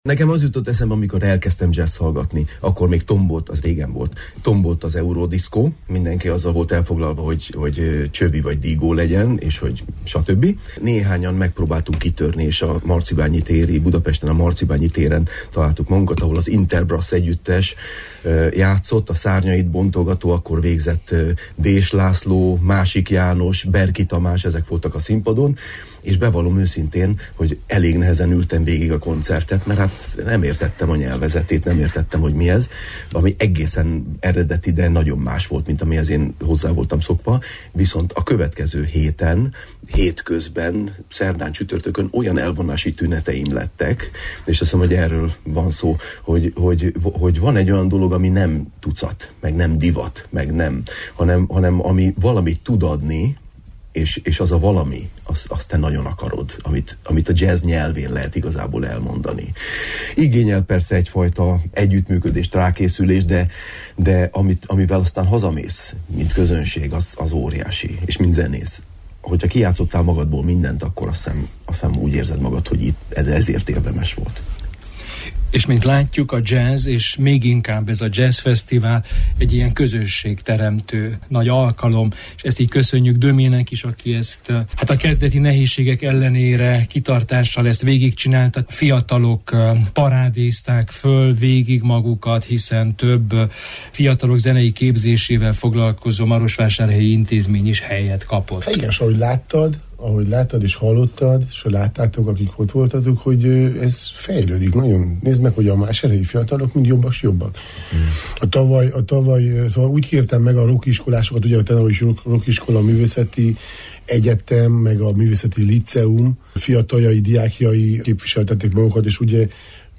jazzgitáros